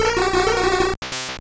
Cri de Soporifik dans Pokémon Or et Argent.